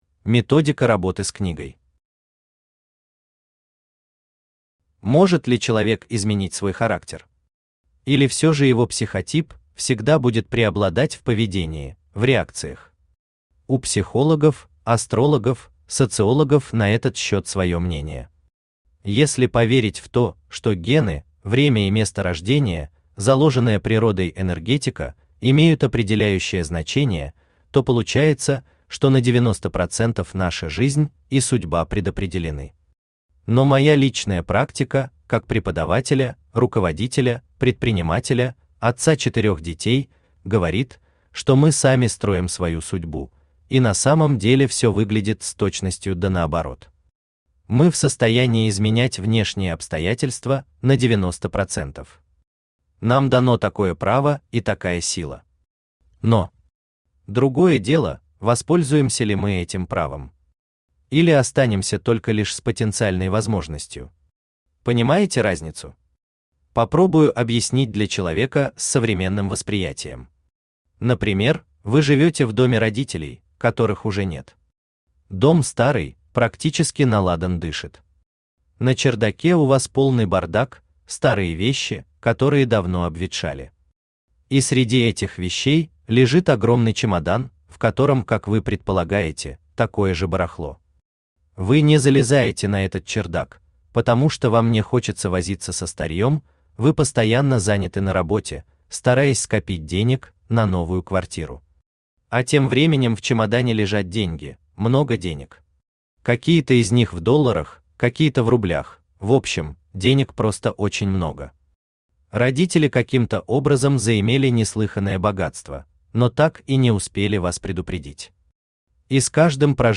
Аудиокнига Сборник самотренингов для риэлтора, или Управление собой и результатом в продажах и переговорах | Библиотека аудиокниг
Aудиокнига Сборник самотренингов для риэлтора, или Управление собой и результатом в продажах и переговорах Автор Вячеслав Александрович Егоров Читает аудиокнигу Авточтец ЛитРес.